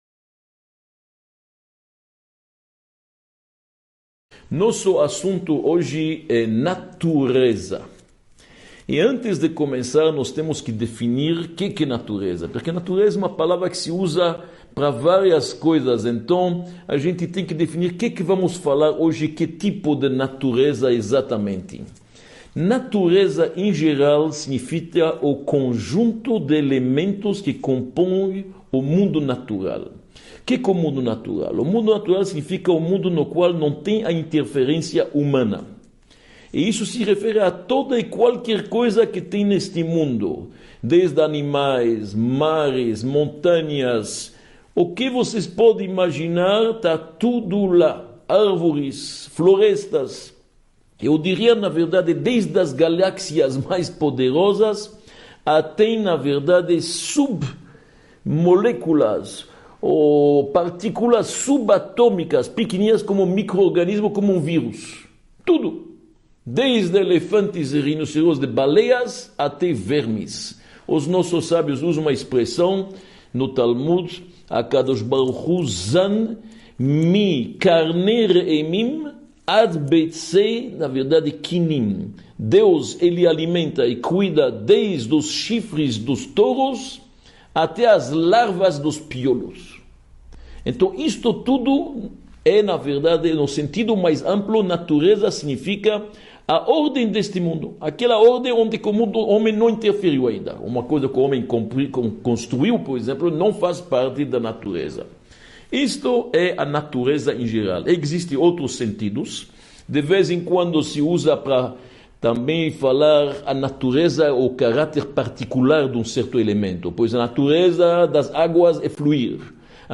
03 – As Leis da Natureza | Os Mistérios do Universo – Aula 03 | Manual Judaico